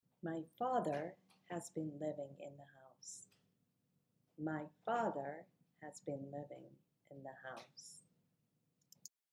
Faterにストレスが置かれる場合